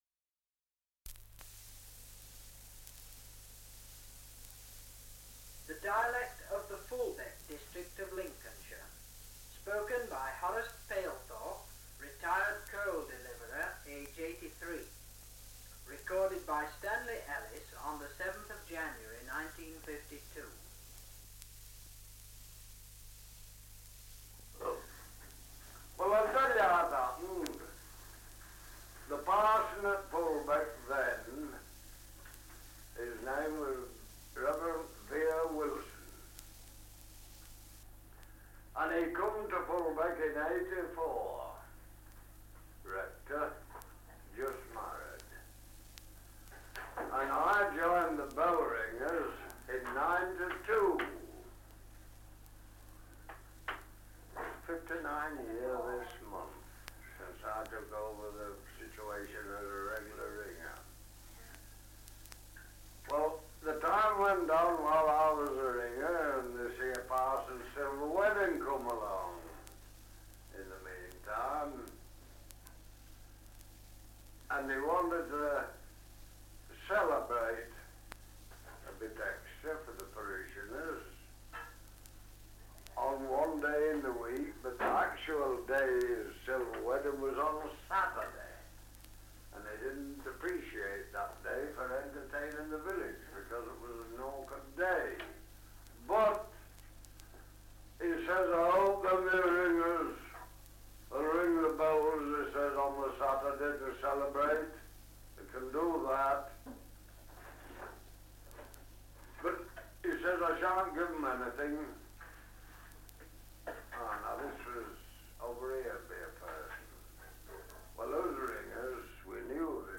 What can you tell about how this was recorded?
Survey of English Dialects recording in Fulbeck, Lincolnshire 78 r.p.m., cellulose nitrate on aluminium